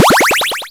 upgrade5.wav